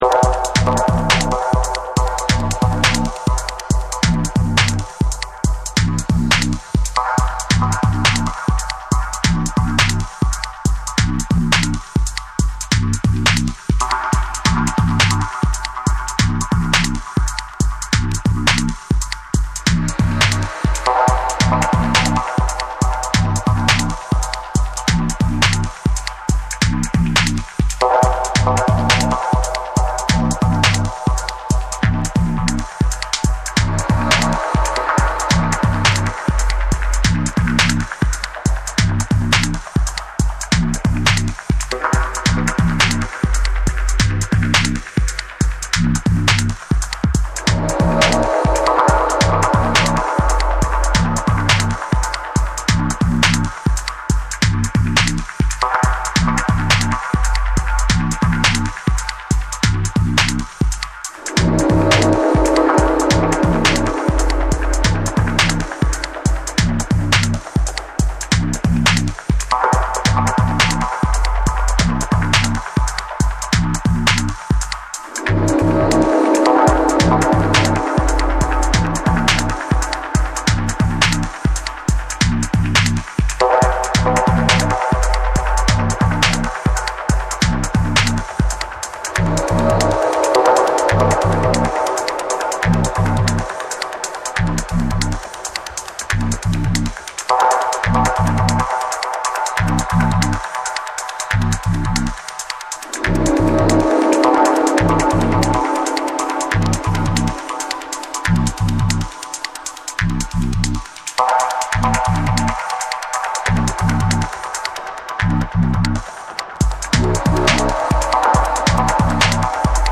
重心の低いサブベースとパーカッシヴなリズムが絡む、ミニマルかつトライバルなダブステップ
BREAKBEATS / DUBSTEP